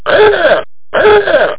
HORN.mp3